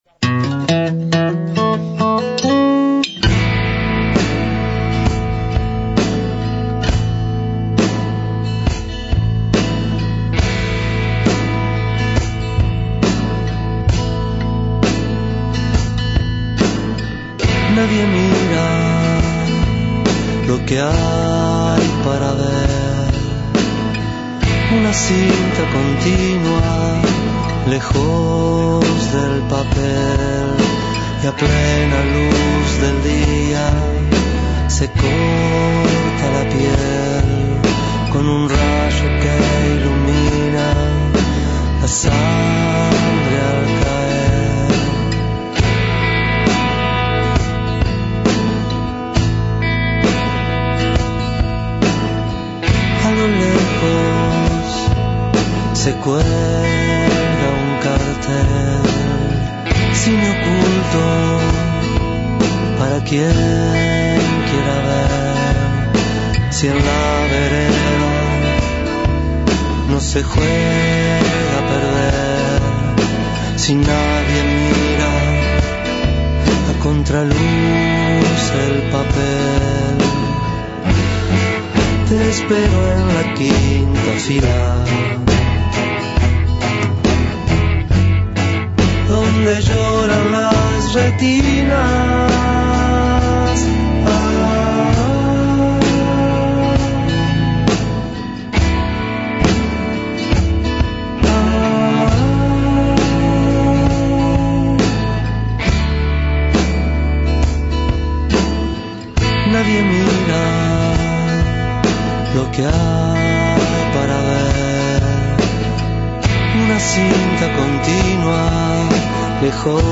Entrevista teléfonica